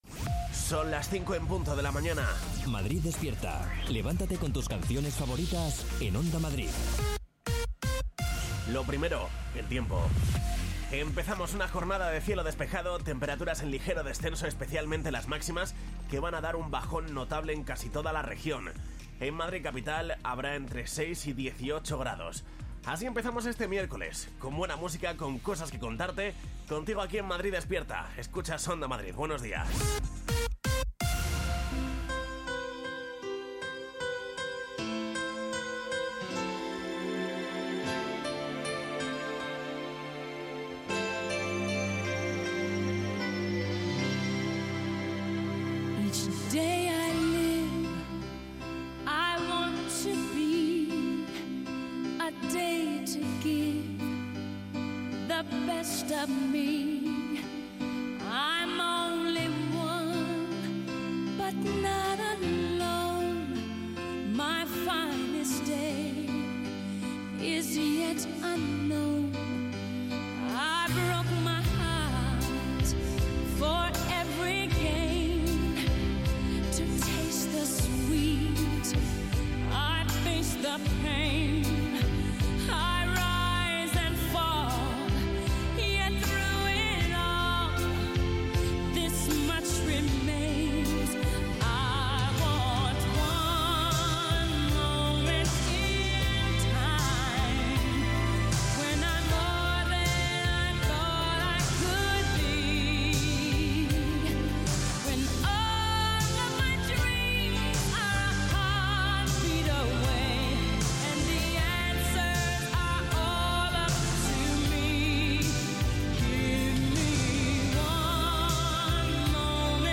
Morning show para despertar a los madrileños con la mejor música y la información útil para afrontar el día.